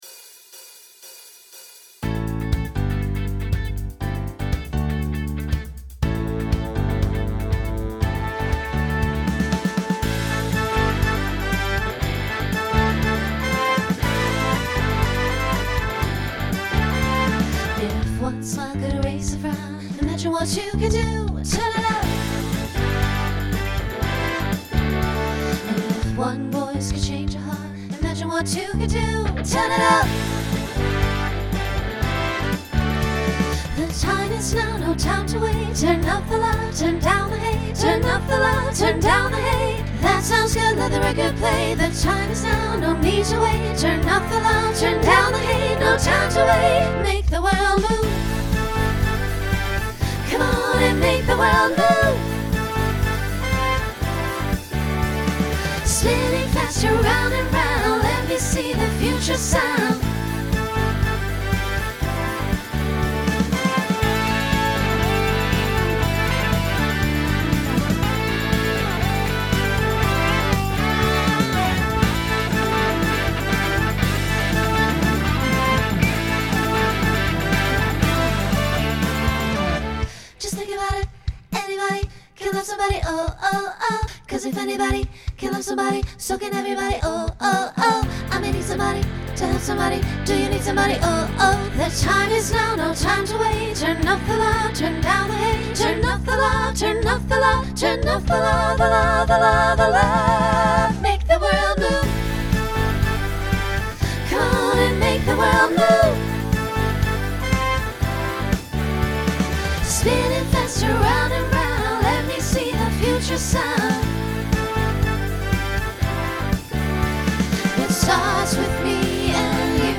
Genre Pop/Dance
Transition Voicing SSA